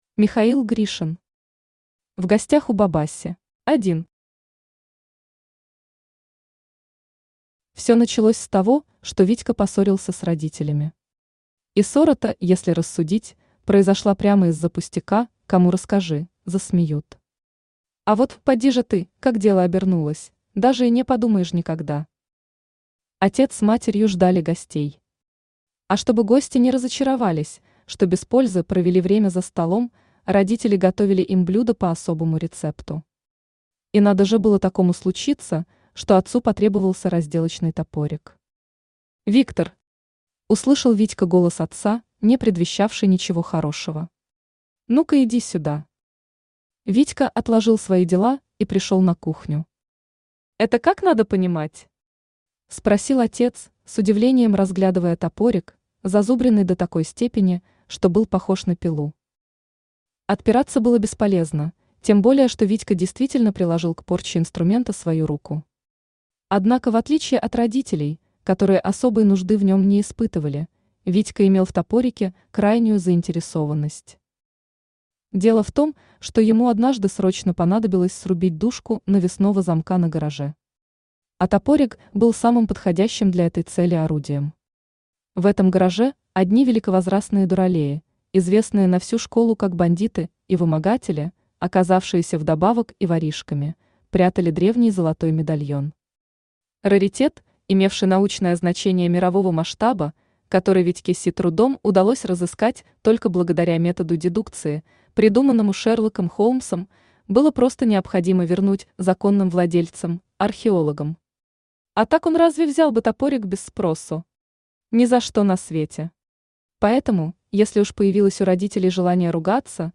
Aудиокнига В гостях у Бабаси Автор Михаил Анатольевич Гришин Читает аудиокнигу Авточтец ЛитРес.